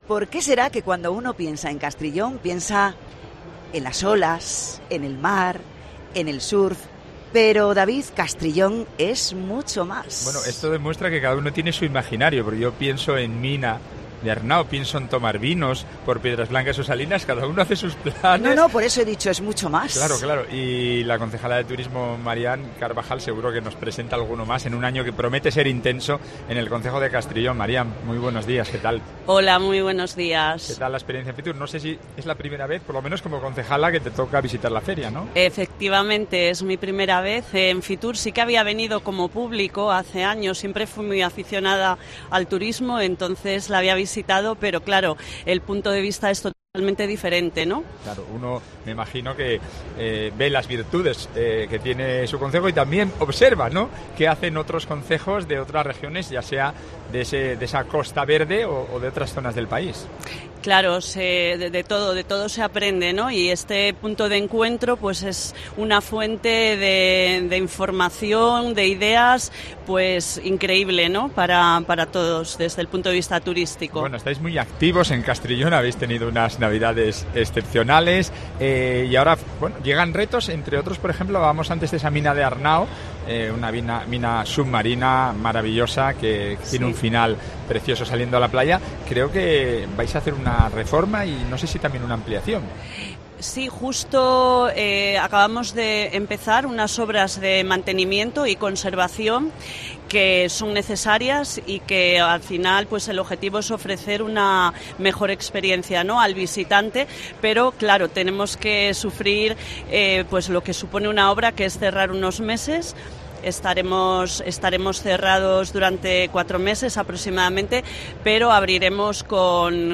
FITUR 2024: Entrevista a Marián Carbajal, concejala de Turismo de Castrillón